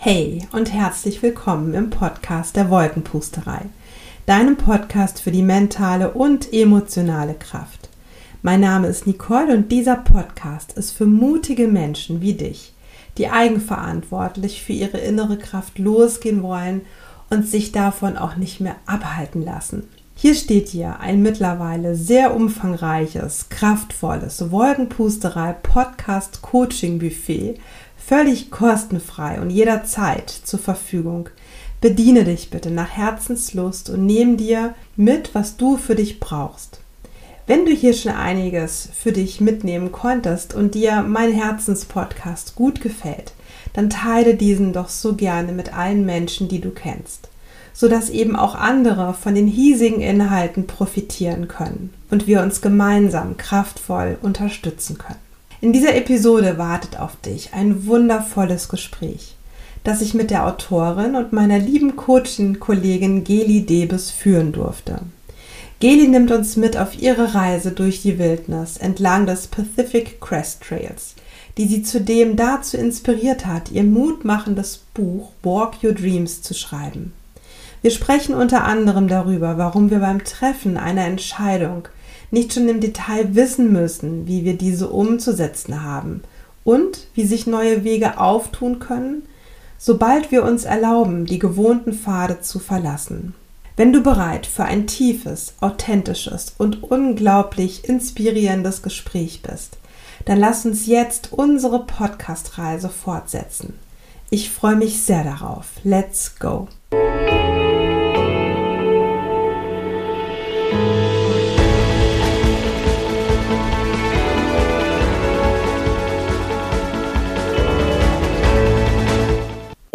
#47 Eine Reise zu dir selbst - Inspirations-Gespräch